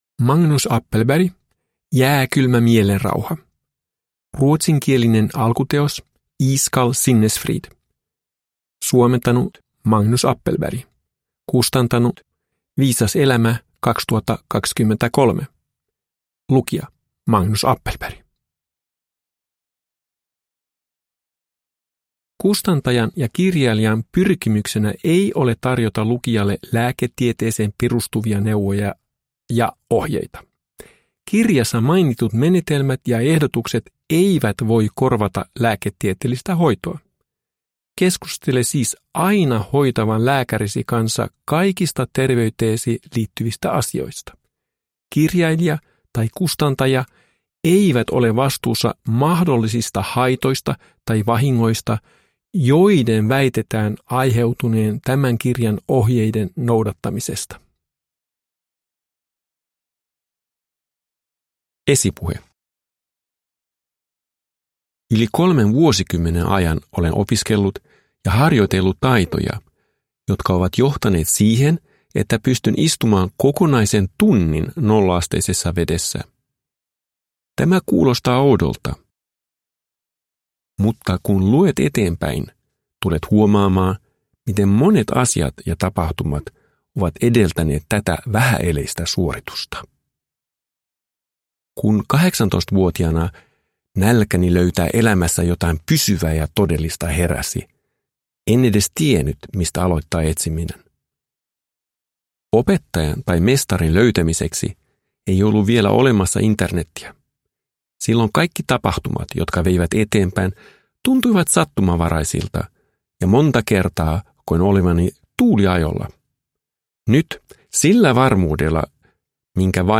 Jääkylmä mielenrauha – Ljudbok